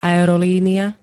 aerolínia [a-er-n-] -ie pl. G -ií D -iám L -iách ž.
Zvukové nahrávky niektorých slov